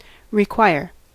Ääntäminen
IPA : /ɹɪˈkwʌɪə/